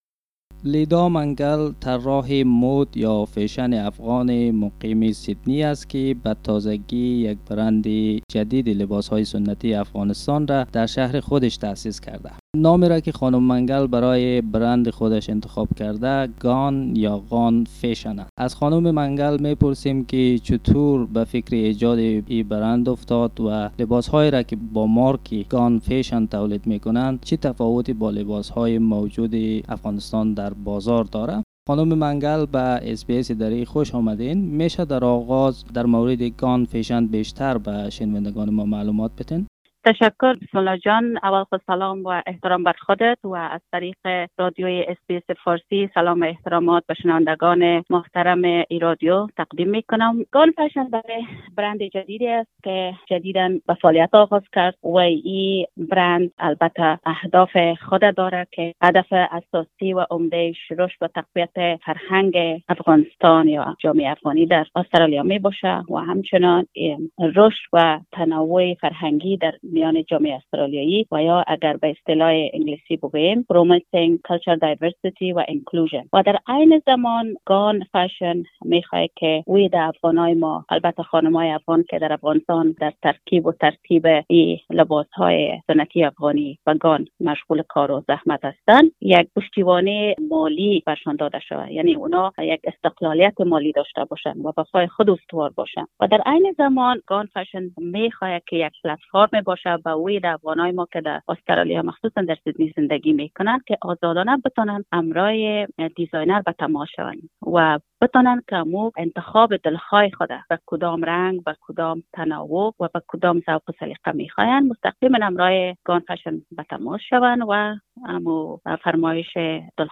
The interview is in the Dari language